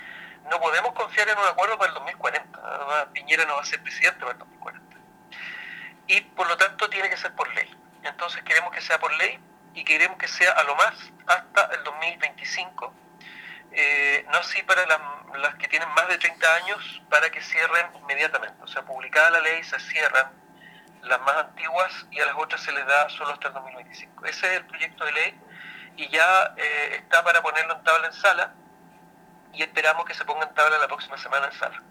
Un llamado a levantar los cordones sanitarios e implementar medidas más estrictas destinadas a impedir la propagación del Covid-19 en la Región del Biobío efectuó el diputado ecologista Félix González en conversación con Nuestra Pauta.